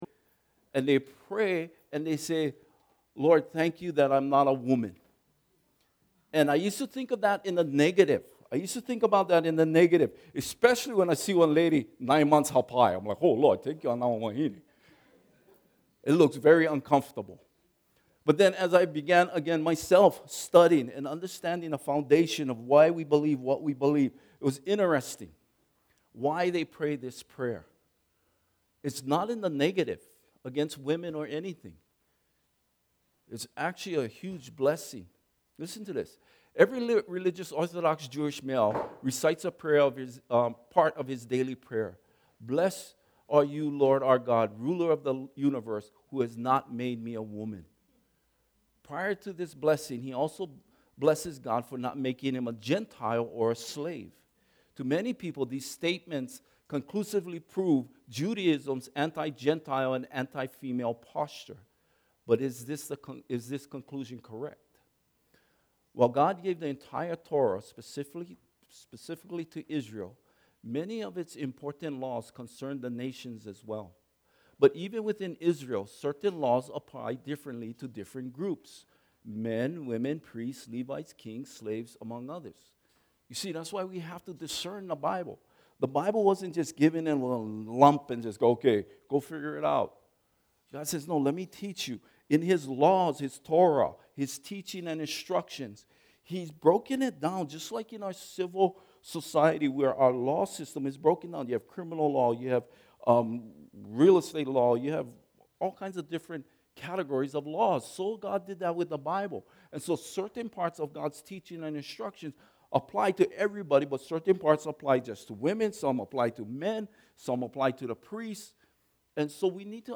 Topical Sermons - Imiola Church